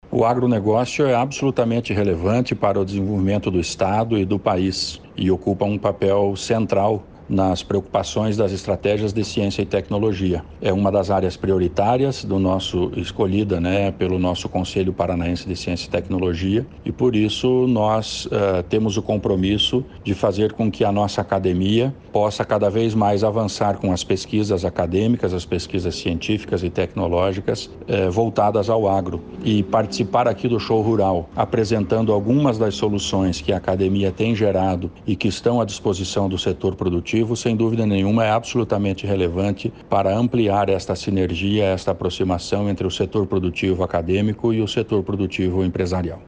Sonora do secretário de Ciência, Tecnologia e Ensino Superior, Aldo Bona, sobre as ações da pasta no Show Rural 2023